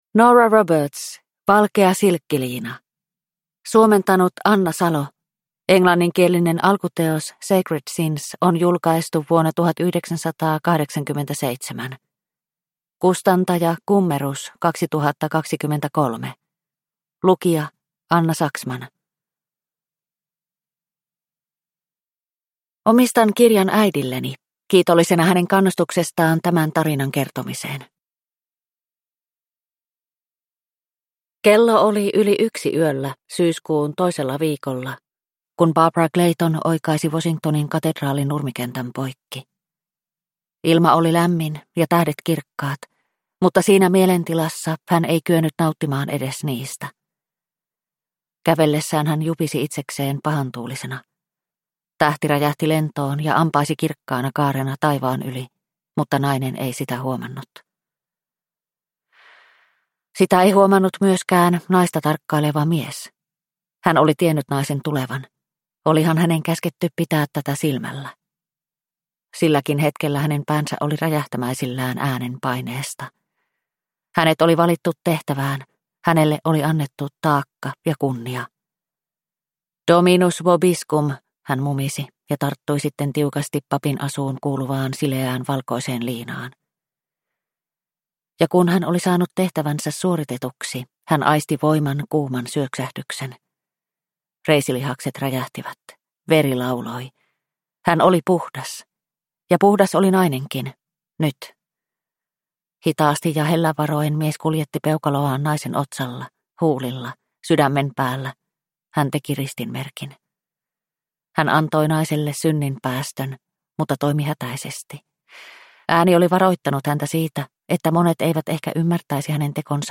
Valkea silkkiliina – Ljudbok – Laddas ner